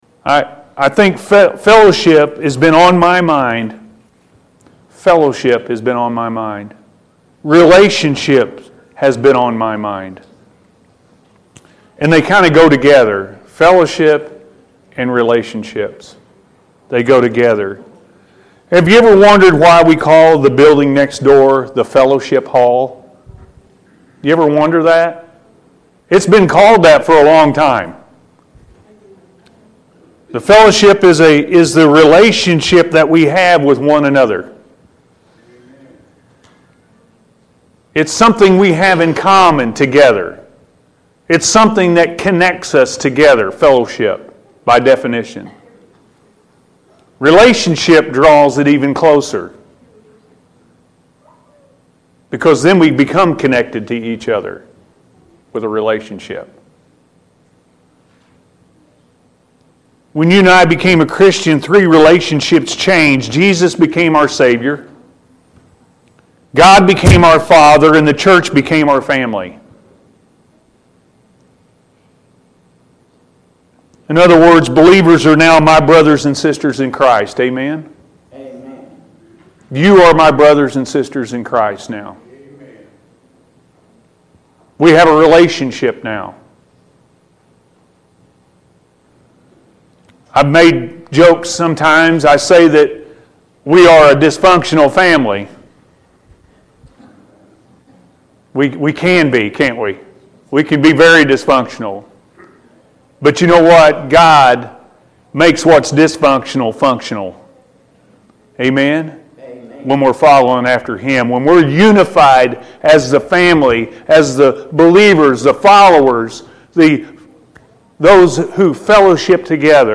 Fellowship and Relationships-A.M. Service